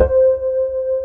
BAS.FRETC5-R.wav